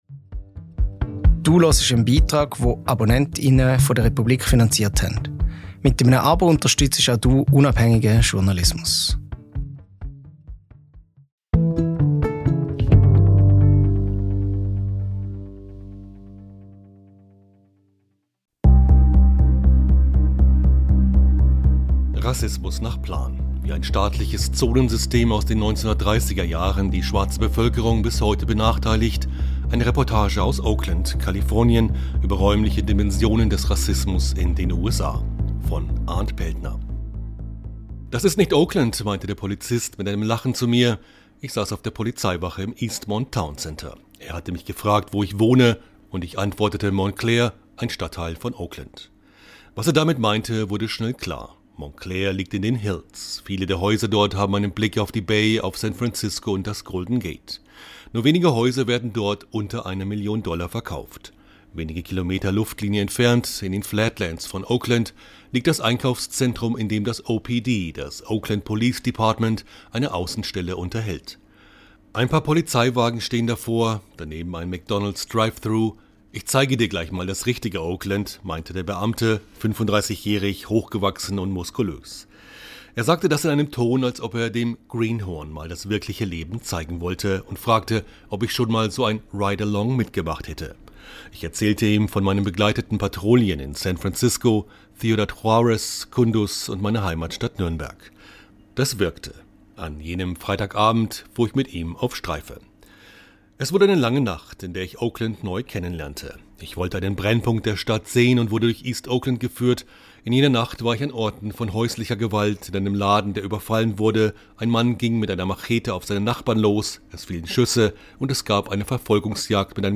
Wie ein staatliches Zonensystem aus den 1930er-Jahren die schwarze Bevölkerung bis heute benachteiligt: eine Reportage aus Oakland, Kalifornien, über räumliche Dimensionen des Rassismus in den USA.